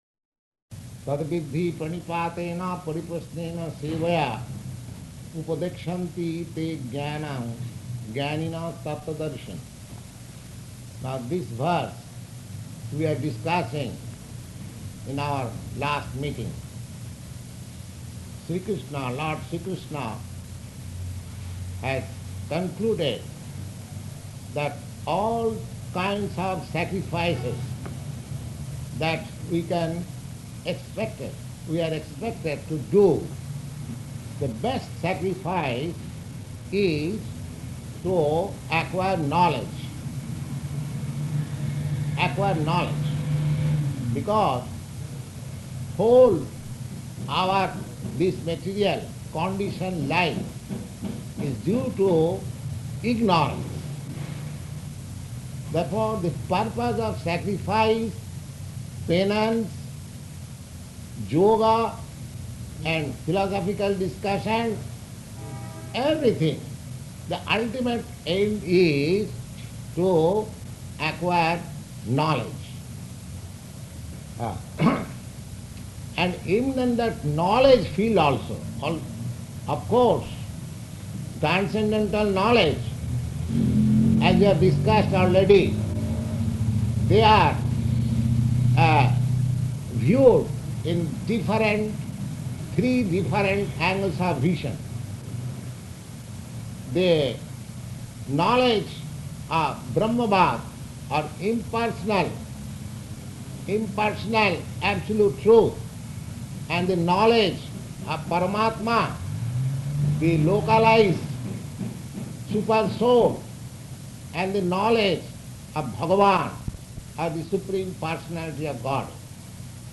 Type: Bhagavad-gita
Location: New York